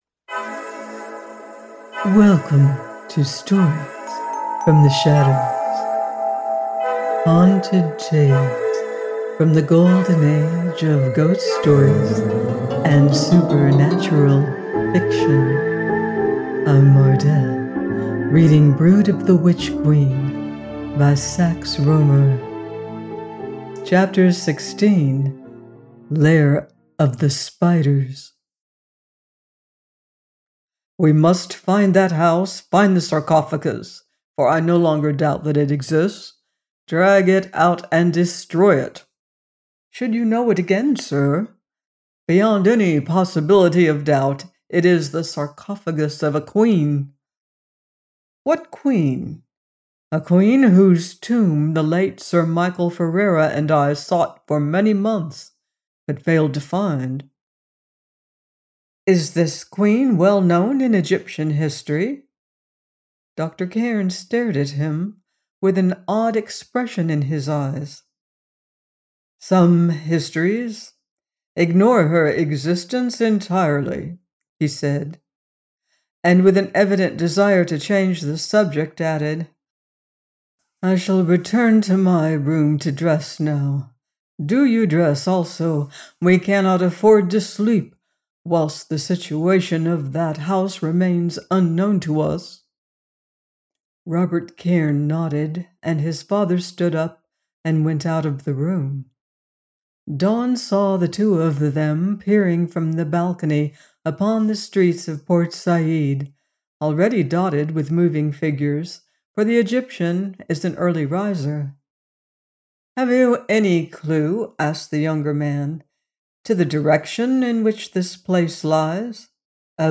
Brood of the Witch Queen – 16 : by Sax Rohmer - AUDIOBOOK